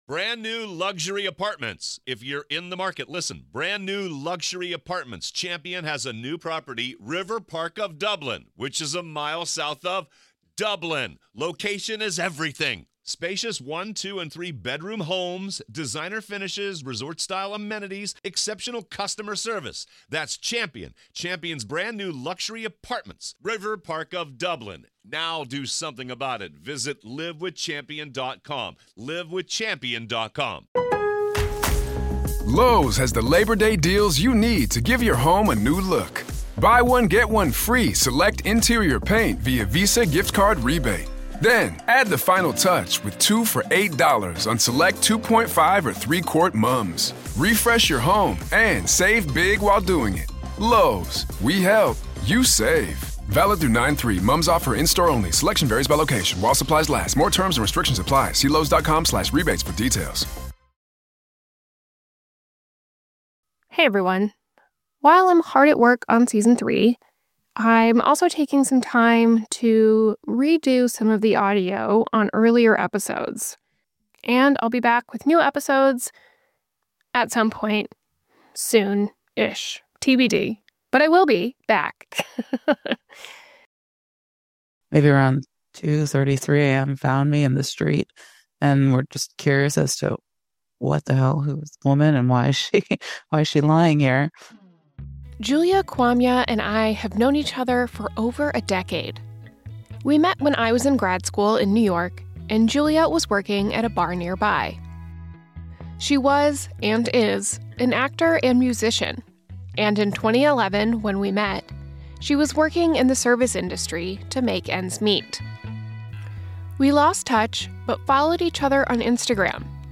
While the show is on hiatus, I'm re-releasing some episodes with better audio quality.